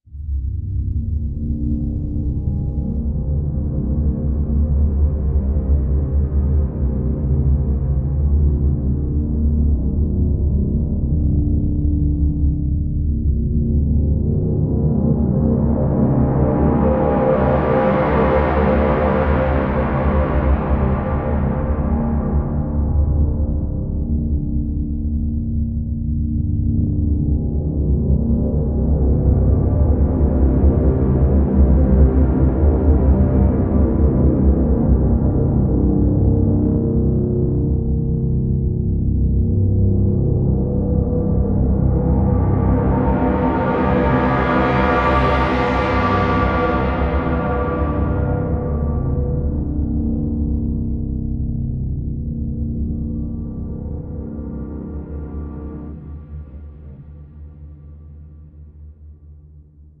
em1-dead-space-1.ogg